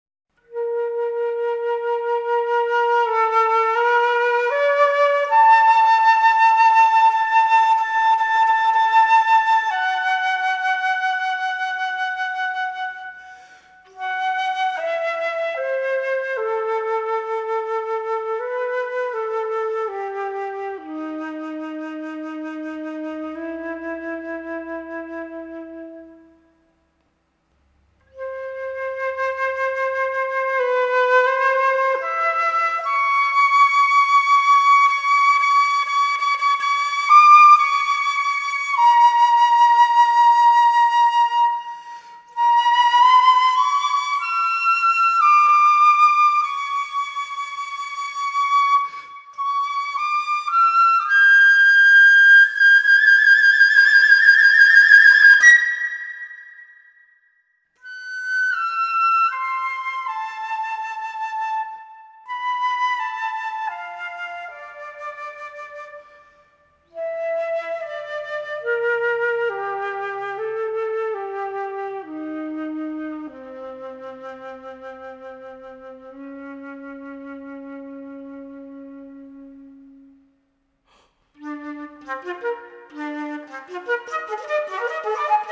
per Flauto solo